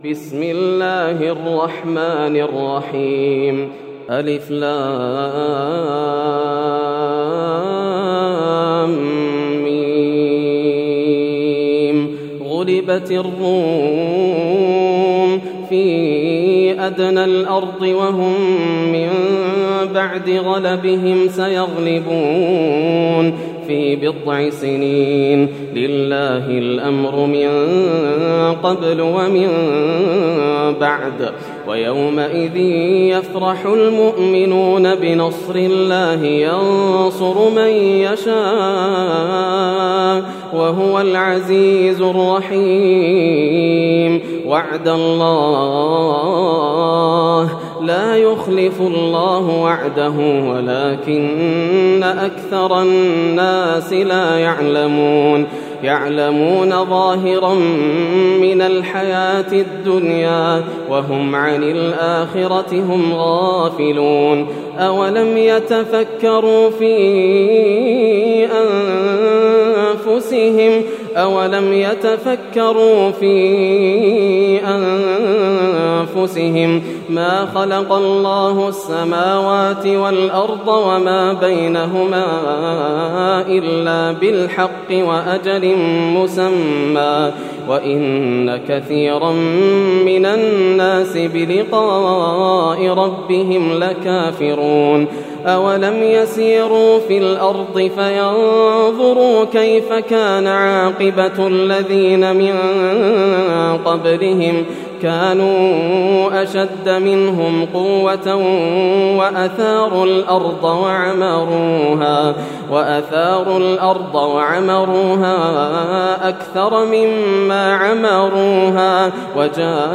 سورة الروم > السور المكتملة > رمضان 1431هـ > التراويح - تلاوات ياسر الدوسري